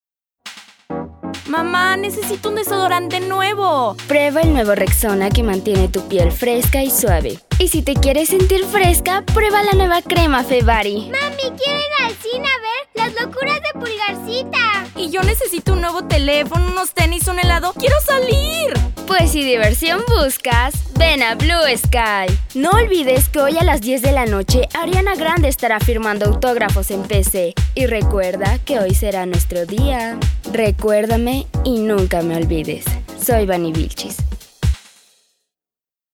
西班牙语女声
低沉|激情激昂|大气浑厚磁性|沉稳|娓娓道来|科技感|积极向上|时尚活力|神秘性感|调性走心|亲切甜美|素人